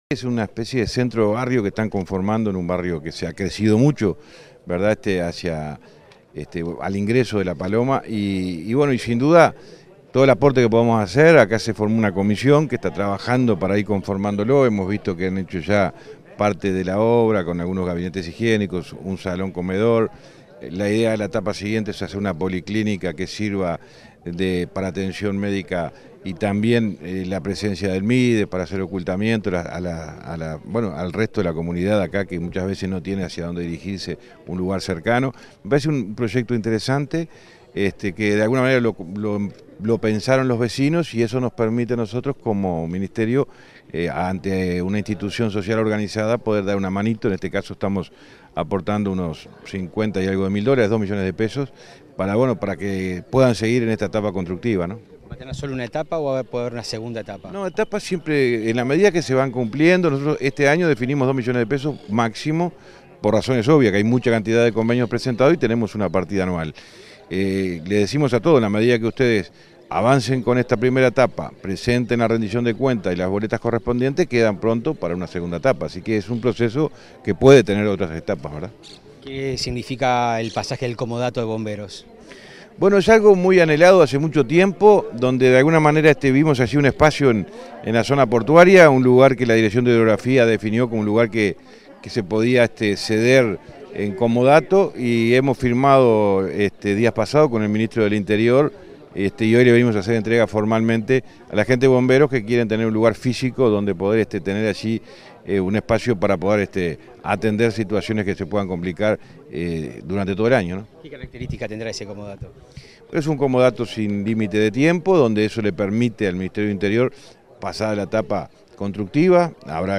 Entrevista al ministro de Transporte, José Luis Falero, en Rocha
Entrevista al ministro de Transporte, José Luis Falero, en Rocha 28/12/2023 Compartir Facebook X Copiar enlace WhatsApp LinkedIn Este 28 de diciembre, el ministro de Transporte y Obras Públicas, José Luis Falero, dialogó con Comunicación Presidencial, en el marco de una recorrida por el departamento de Rocha.